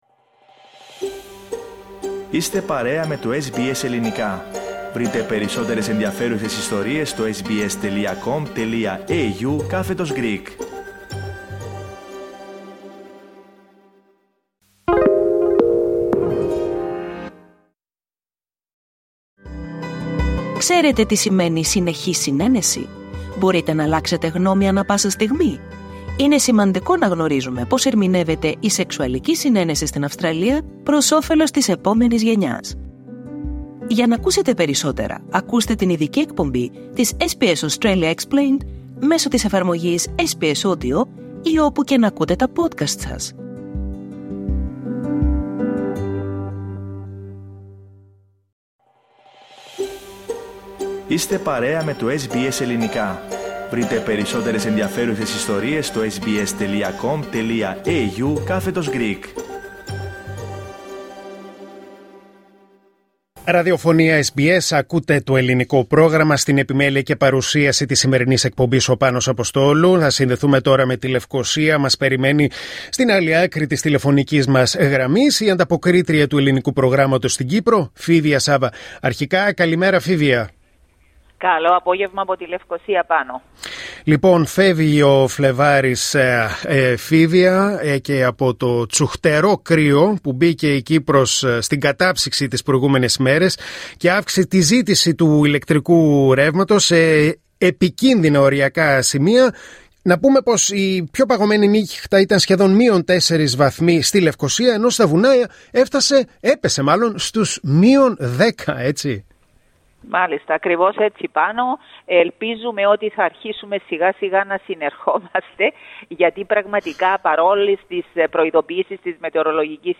Ακούστε την εβδομαδιαία ανταπόκριση από την Κύπρο